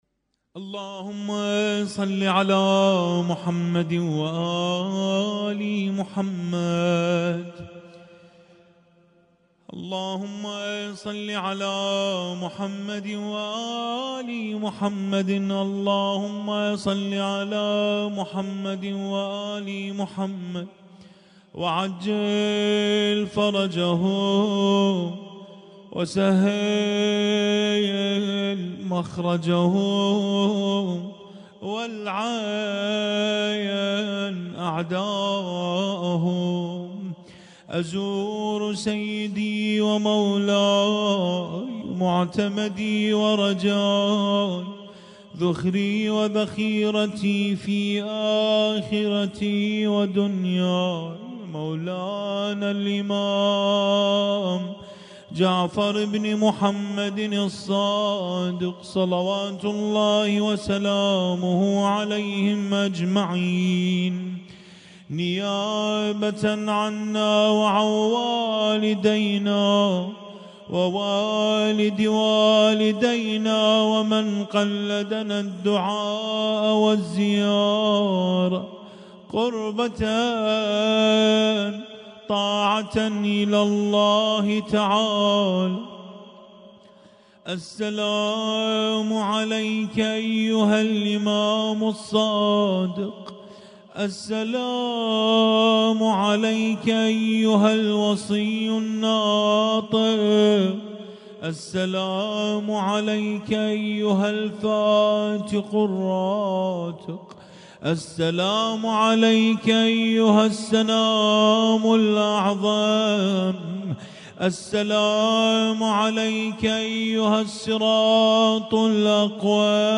القارئ: الرادود